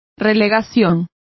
Complete with pronunciation of the translation of relegation.